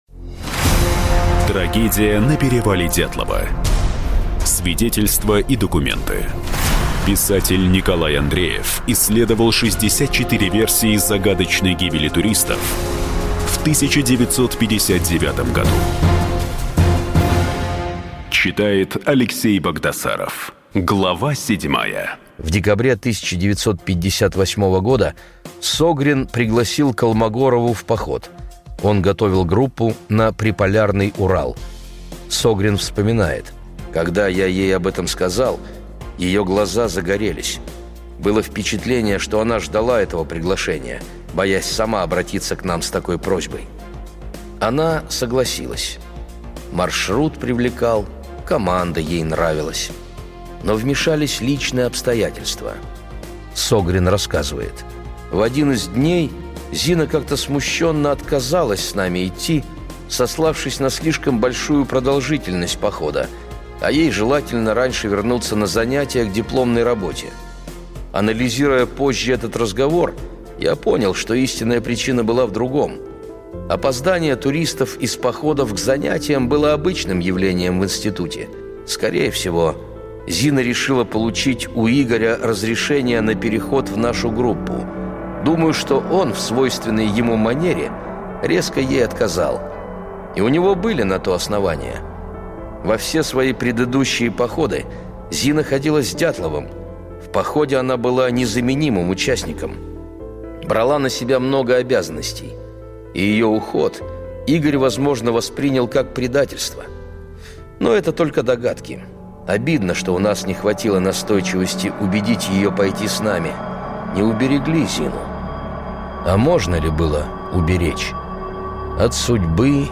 Аудиокнига Трагедия на перевале Дятлова: 64 версии загадочной гибели туристов в 1959 году. Часть 7 и 8 | Библиотека аудиокниг